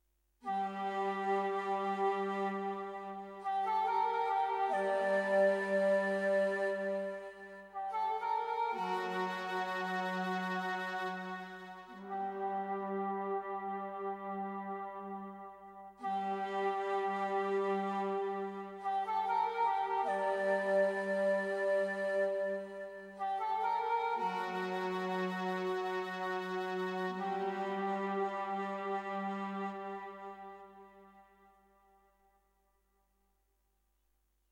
Música ambiental del cuento: El príncipe serpiente
ambiente
melodía
sintonía